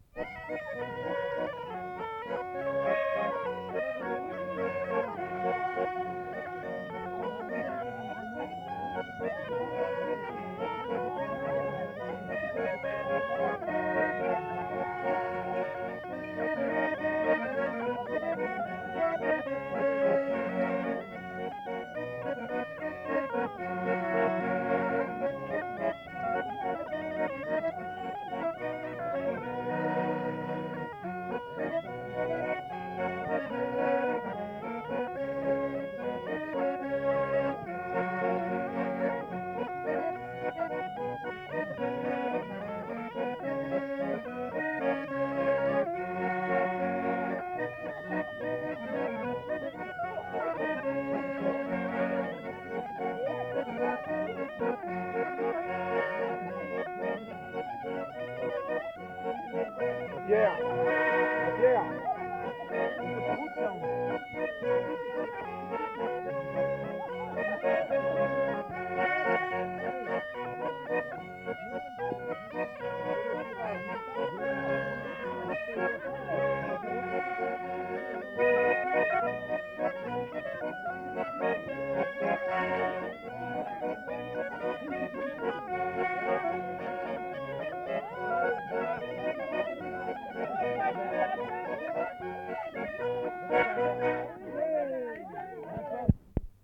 Aire culturelle : Viadène
Département : Aveyron
Genre : morceau instrumental
Instrument de musique : cabrette ; accordéon chromatique
Danse : valse
• [enquêtes sonores] Veillée Aligot au Quié